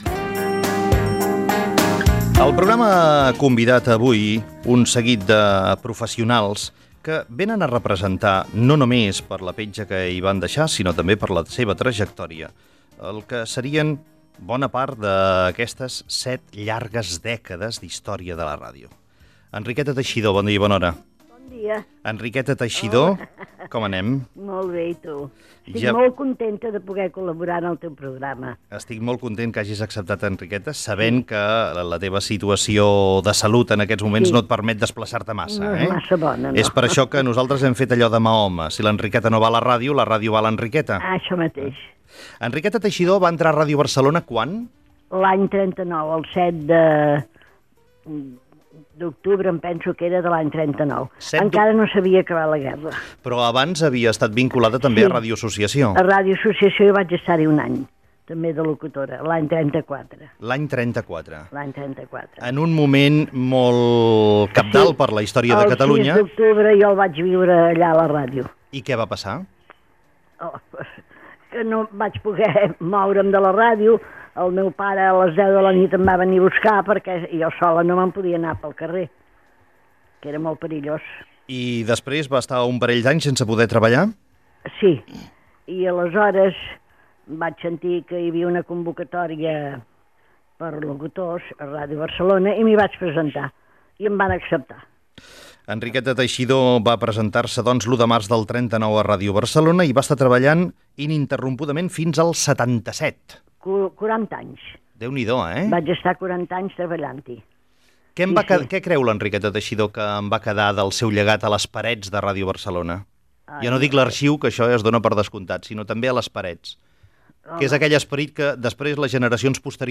75è aniversari de Ràdio Barcelona. Conversa
Info-entreteniment
FM